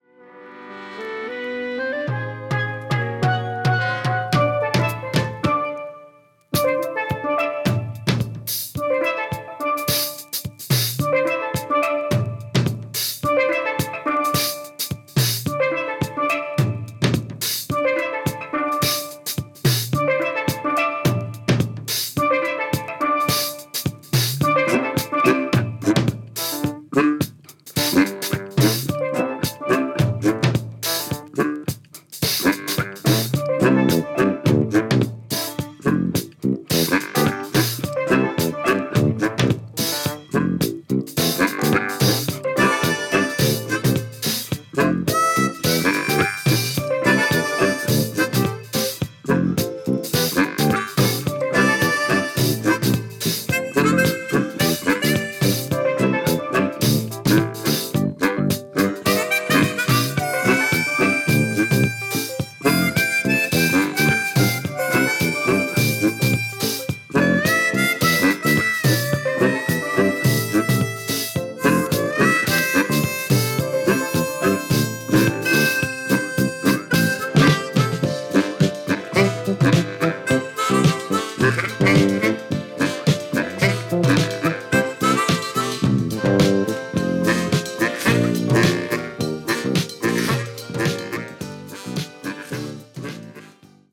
Condition Media : VG+(擦れ、チリチリ)